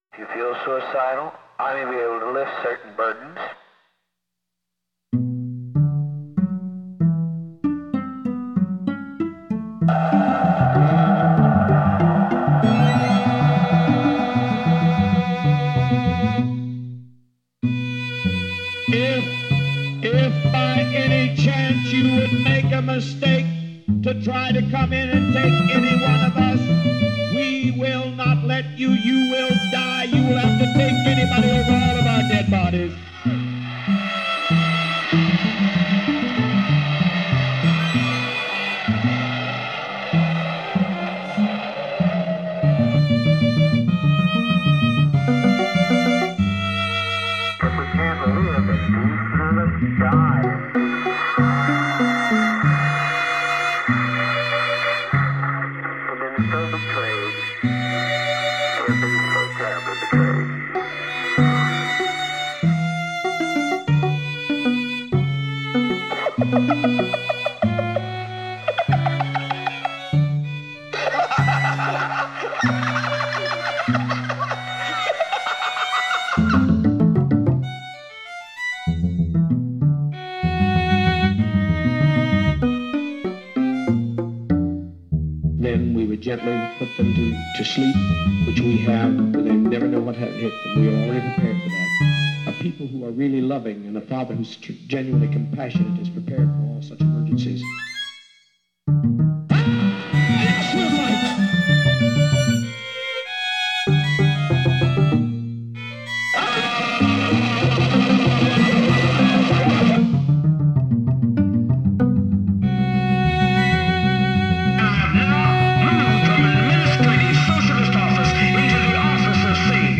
string quartet
The realization heard here is from a mid-1990s sampling keyboard mockup.
Like “Think Back,” Soul Seduction” uses Hungarian modes to generate the desired air of creepiness. But unlike the elastic-metered “Think Back,” “Soul Seduction” is scored in the form of three funk movements and an opening African-inspired movement.
Movement Four pulls material from a “white night” – basically a dress rehearsal for the inevitable mass suicide – during which Jones whips his followers into a frenzy, convincing them that they are about to be attacked by armed forces from the U.S. Like many classical works before it, the movement quotes the “Dies Irae” (Day of Wrath) Gregorian plainchant from the mass of the dead.